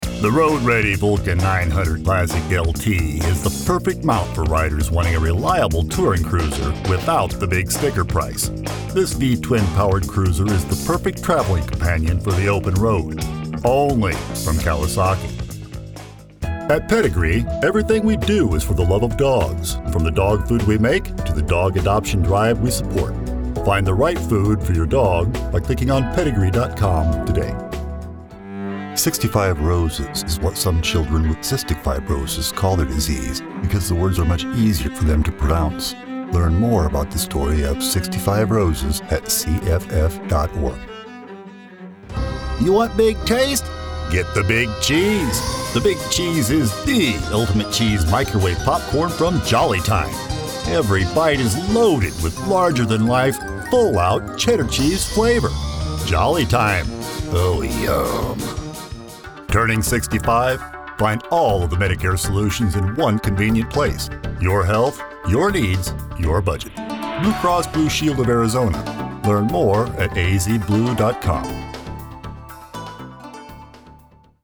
Deep, resonant, unique, genuine, masculine, weathered, authoritative, commanding, seasoned, distinctive.
middle west
Sprechprobe: Werbung (Muttersprache):